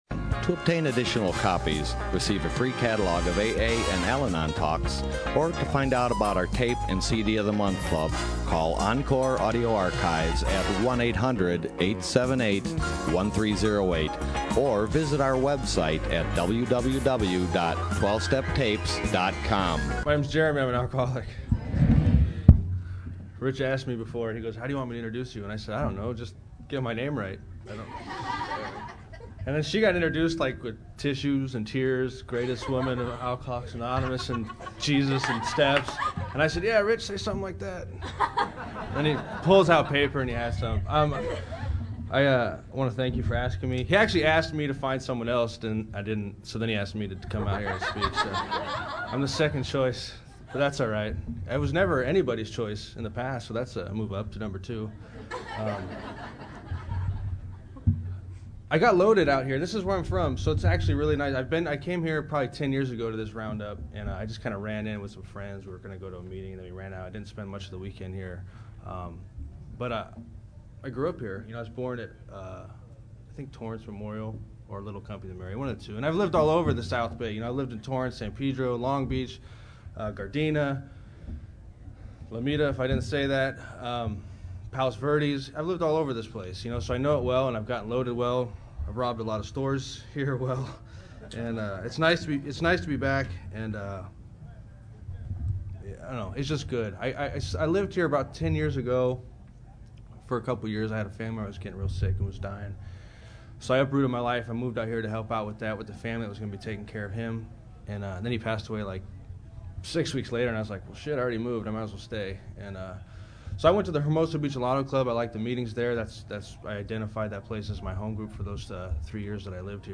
SOUTHBAY ROUNDUP 2012